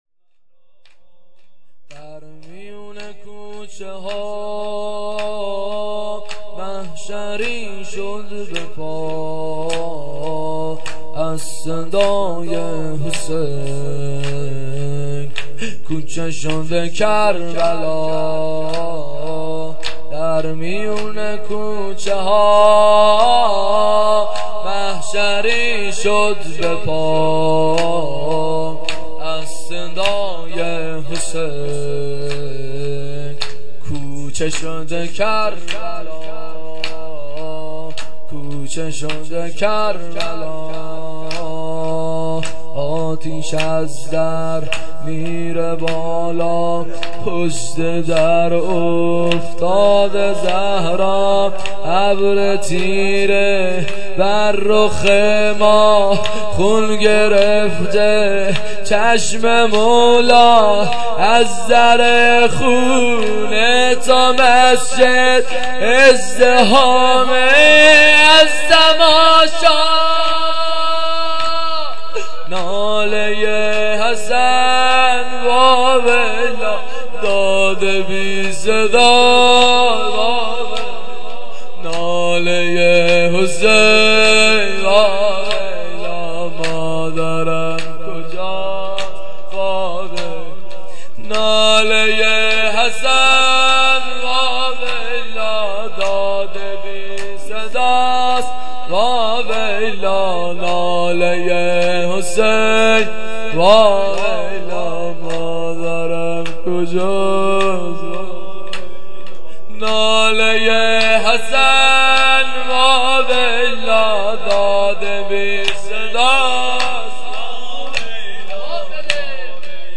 شب دوم فاطمیه
• محفل قدس فاطمیه دوم 92